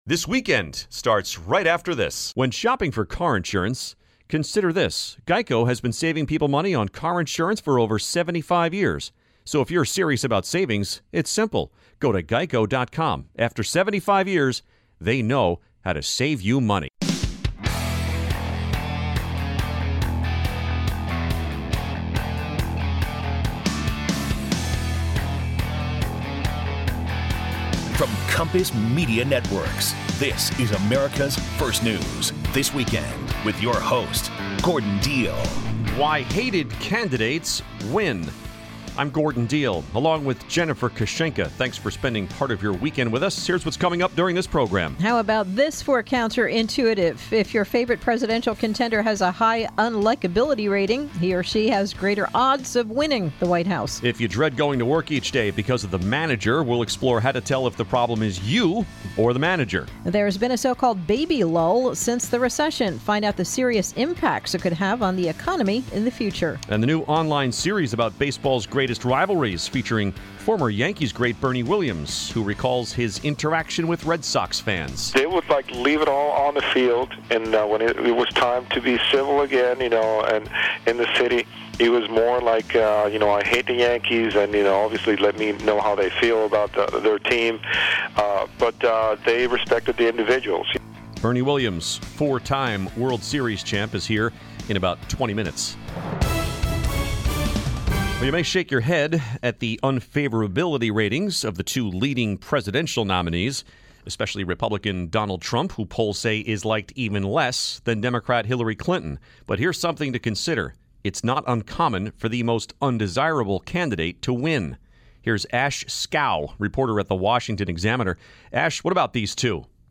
Why the most hated candidate usually wins, how to deal with a manager who hates you and a conversation with Yankees great Bernie Williams.